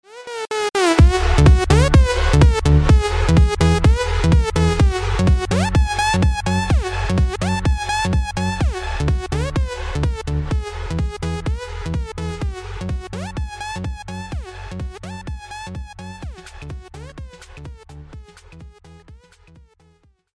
Sound Buttons: Sound Buttons View : Long Drop Alert
long-drop-alert.mp3